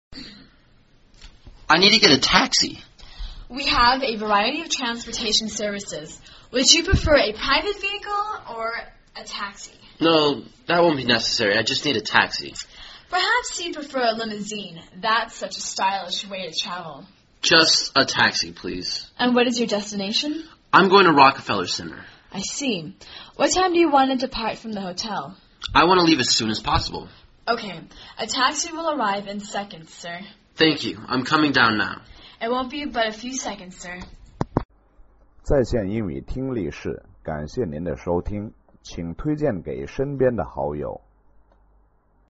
旅馆英语对话-Get a Taxi via(1) 听力文件下载—在线英语听力室